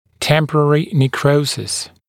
[‘tempərərɪ nek’rəusɪs][‘тэмпэрэри нэк’роусис]временный некроз